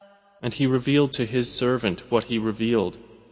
متن، ترجمه و قرائت قرآن کریم